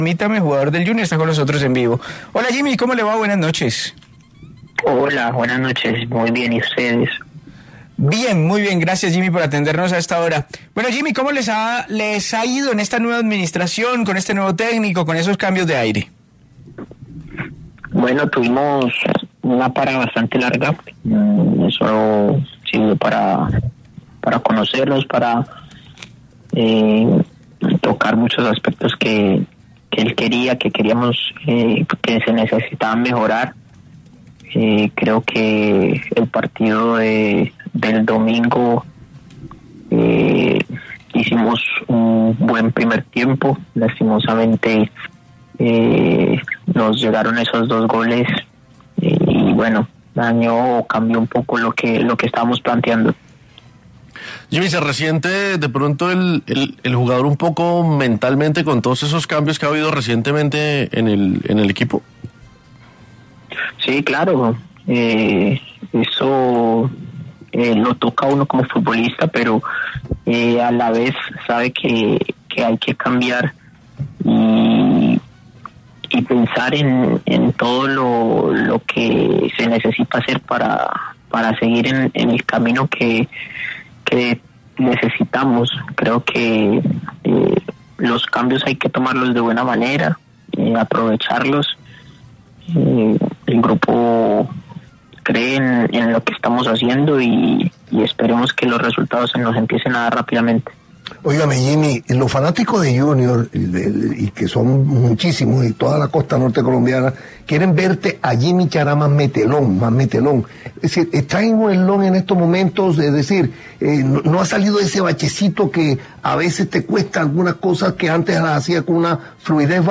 El atacante Yimmi Chará habló con El Alargue de Caracol Radio y reflexionó sobre la situación actual del equipo y el deseo de levantar cabeza.